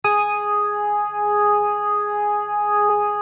B3LESLIE G#5.wav